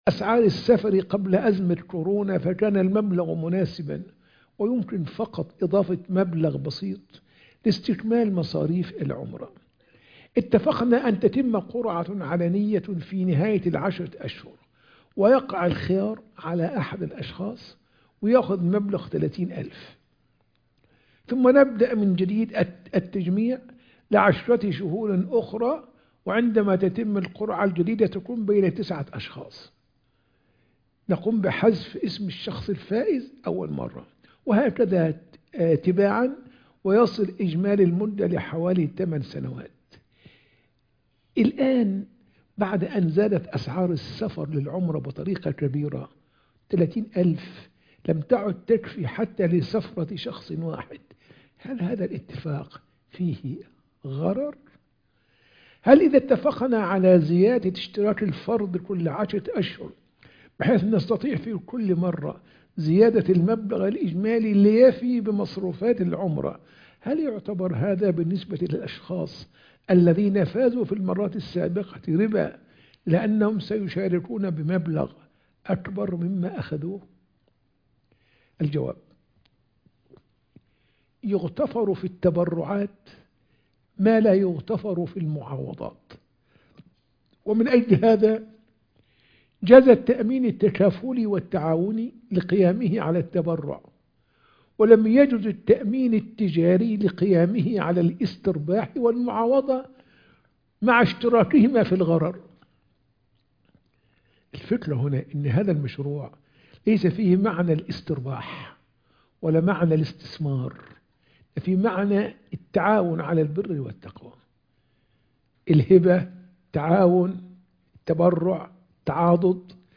فتاوى على الهواء